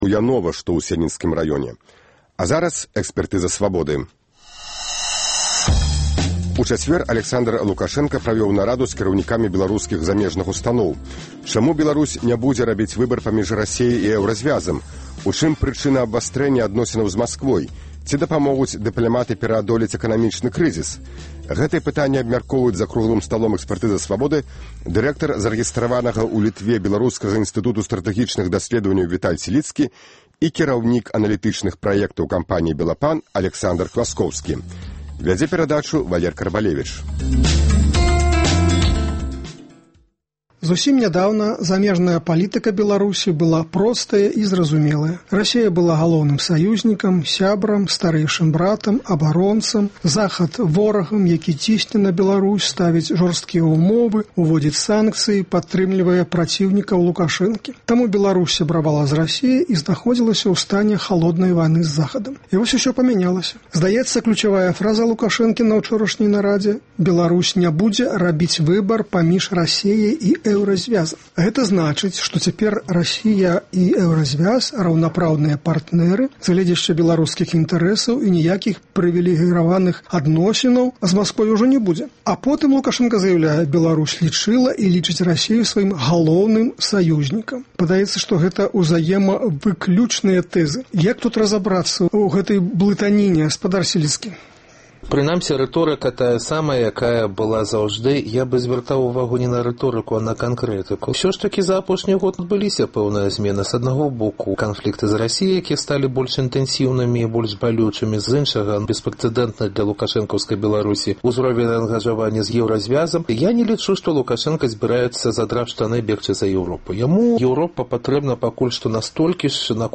Чаму Беларусь ня будзе рабіць выбар паміж Расеяй і Эўразьвязам? У чым прычына абвастрэньня адносінаў з Масквой? Ці дапамогуць дыпляматы пераадолець эканамічны крызыс? Гэтыя пытаньні абмяркоўваюць за круглым сталом